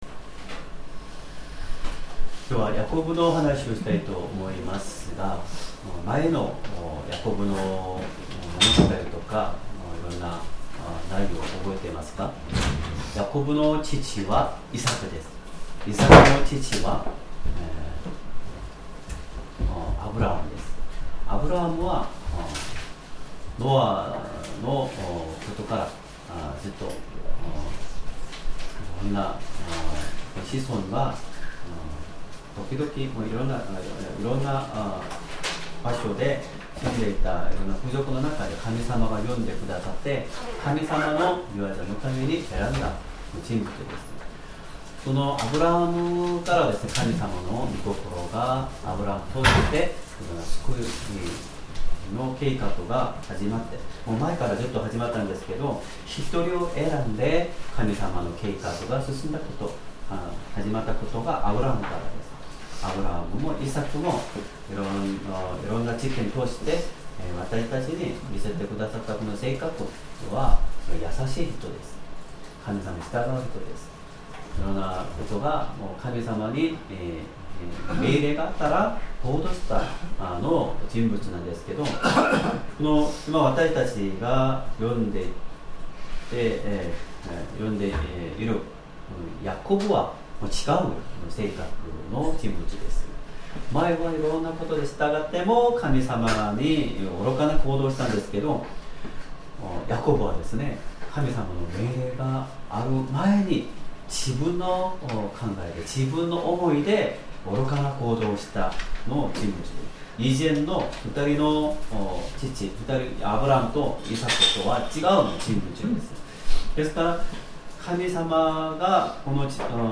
Sermon
2026年3月1日 主日礼拝 説教 「イスラエル、神と戦って、勝つ名前」 聖書 創世記 32章 24-32節 32:24 ヤコブが一人だけ後に残ると、ある人が夜明けまで彼と格闘した。